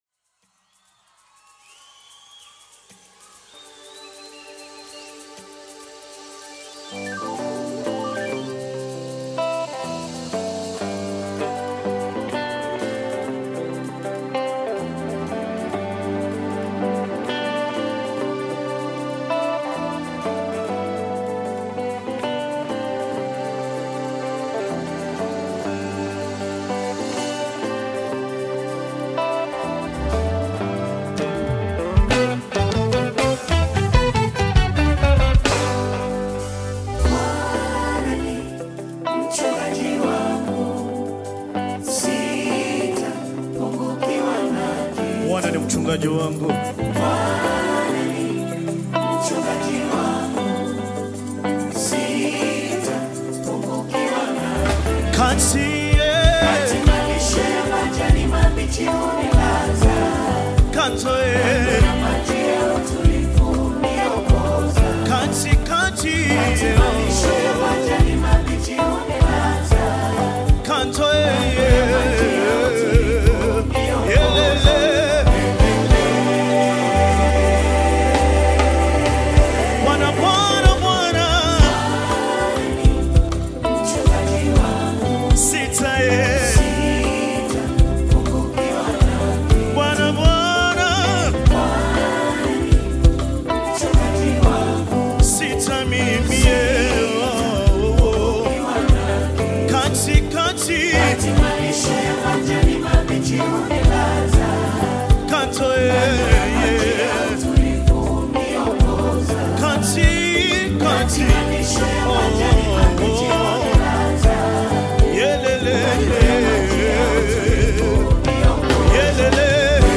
Tanzanian Gospel music group
Gospel song